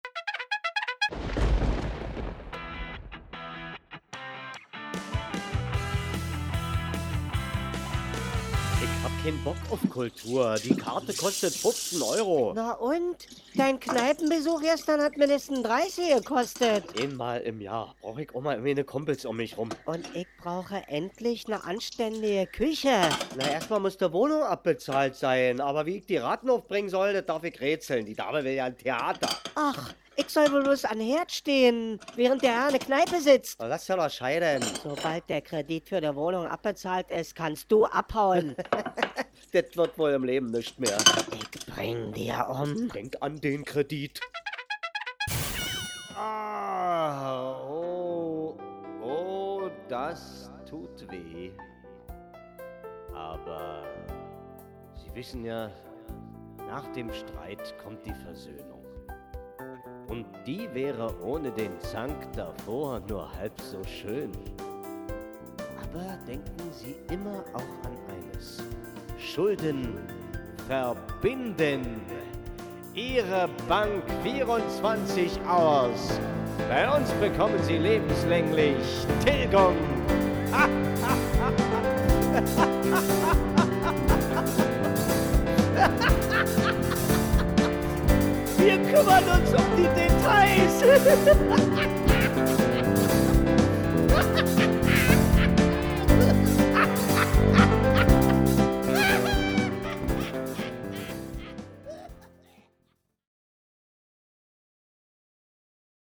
Geschichten, Kabarettszenen, Parodien und Minihörspiele.
Aus eigener Minihörspiel-Serie  mit dem Titel „Kredit“.